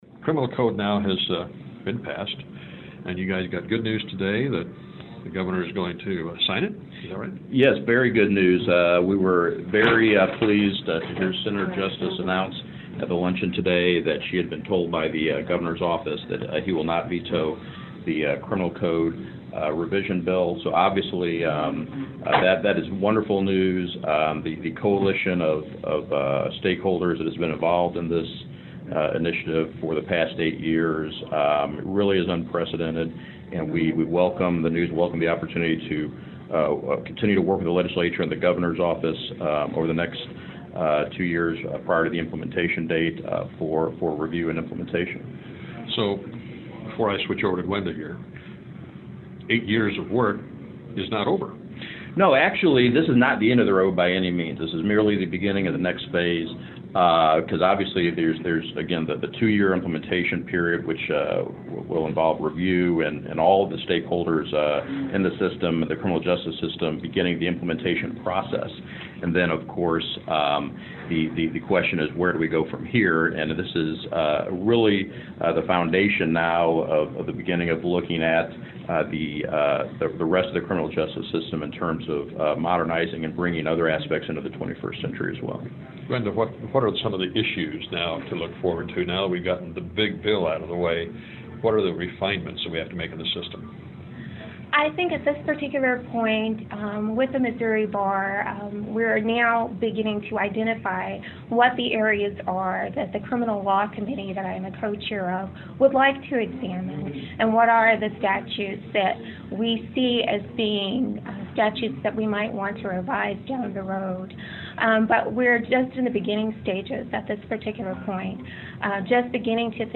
AUDIO: Interview 5:24 Share this: Facebook Twitter LinkedIn WhatsApp Email